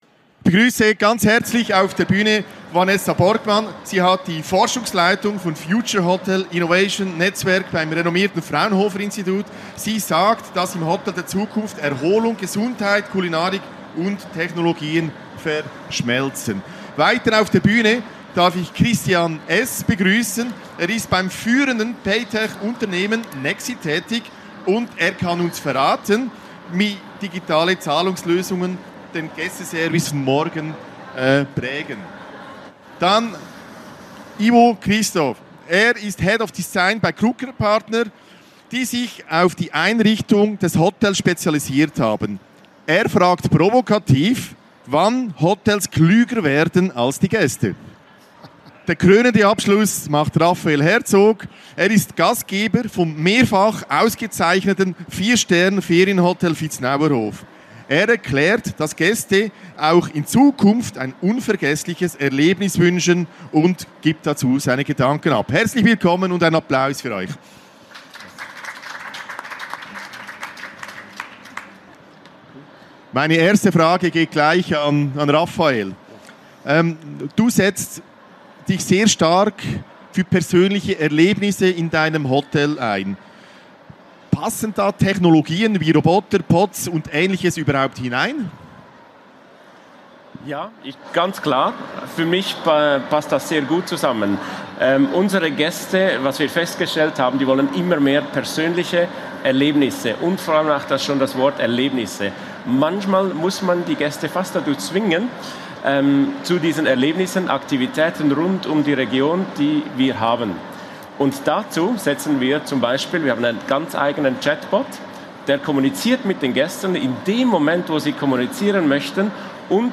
Am 16. November 2025 diskutierte die 5-köpfige Expertengruppe auf der Cheminée-Chats-Bühne der Igeho 2025 über ein mögliches Hotel der Zukunft und die Gästebedürfnisse im Jahr 2085.